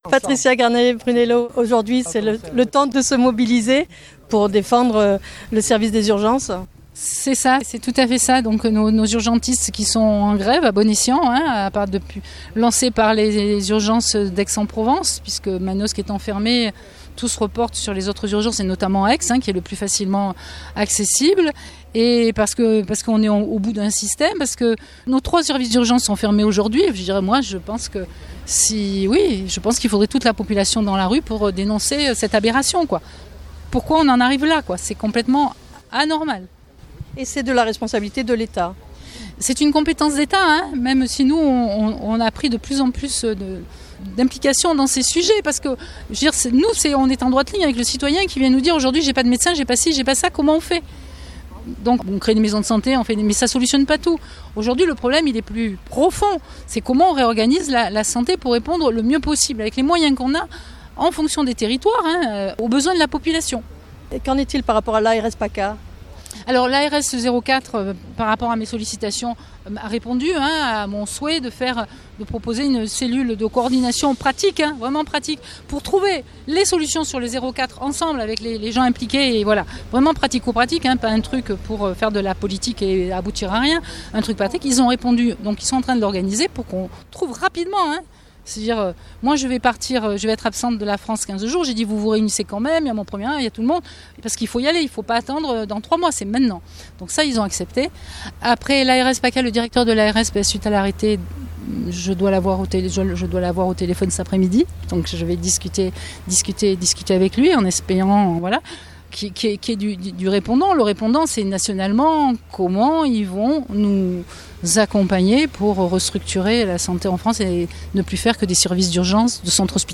" Patricia Granet-Brunello Présidente du Conseil Territorial de santé des Alpes de Haute Provence et Maire de Digne les Bains